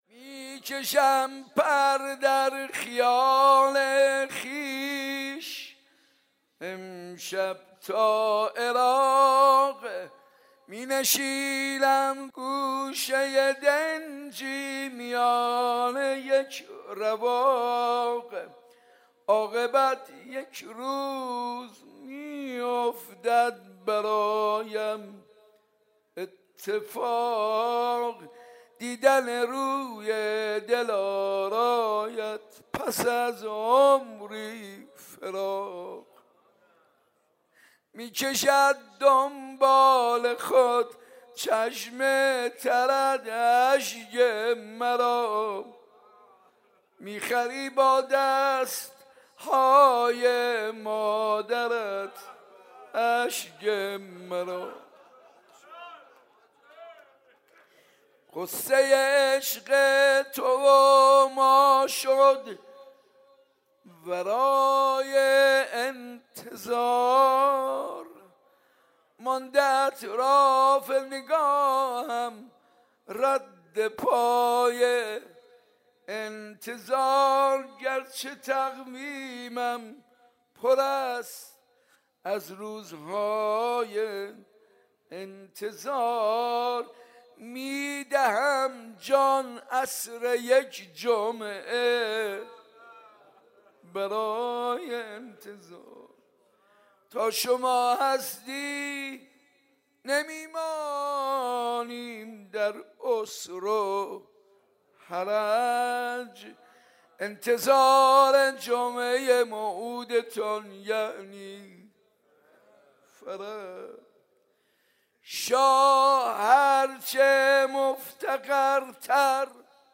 میلاد امام زمان (عج) 95 - روضه - می کشم پر در خیال خویش امشب تا عراق